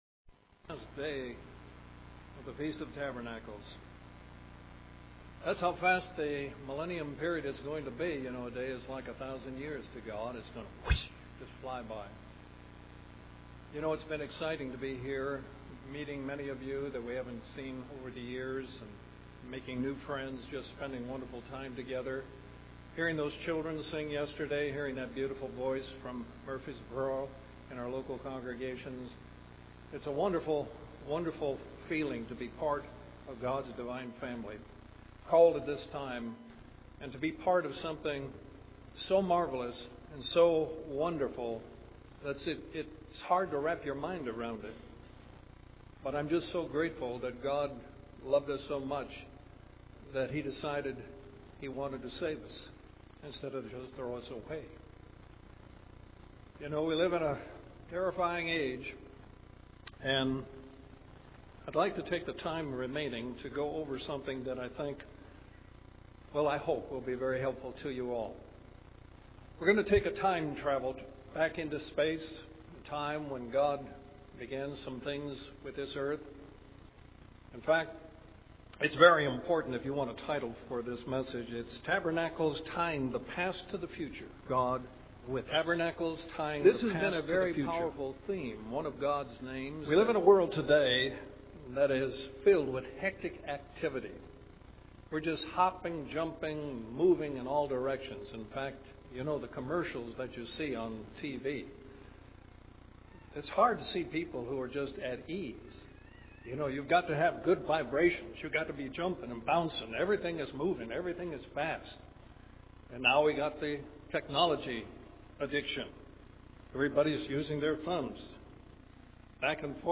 This sermon was given at the Panama City Beach, Florida 2012 Feast site.